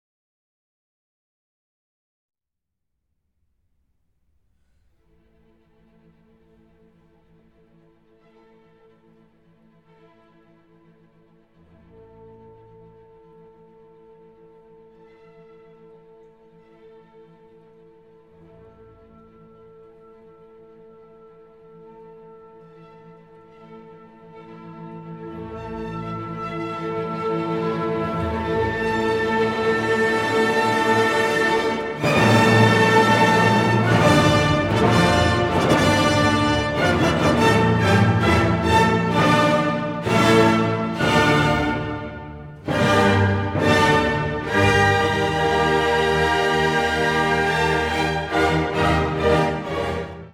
Here’s a bit of a version conducted by Von Karajan in 1993: